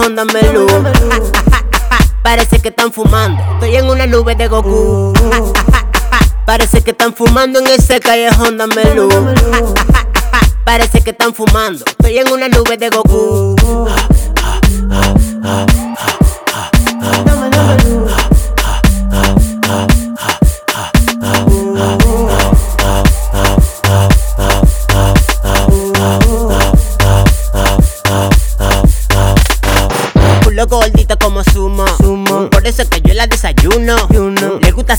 Жанр: Латиноамериканская музыка
# Latino